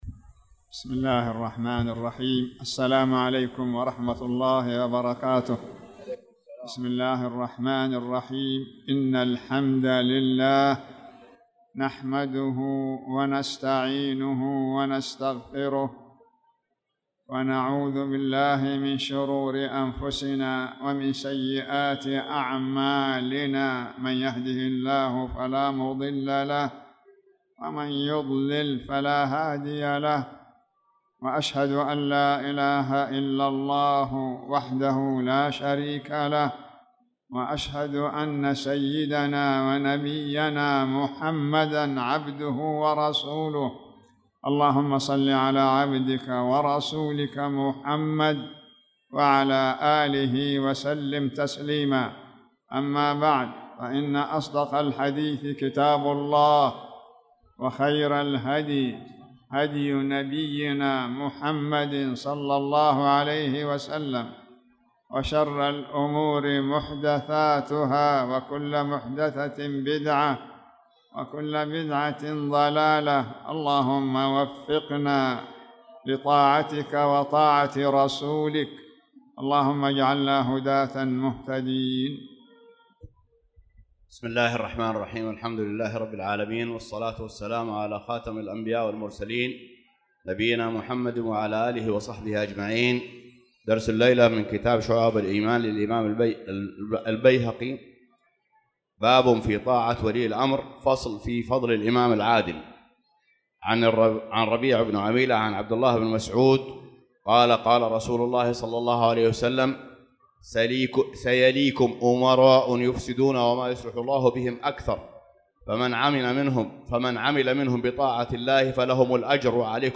تاريخ النشر ٣ شعبان ١٤٣٧ هـ المكان: المسجد الحرام الشيخ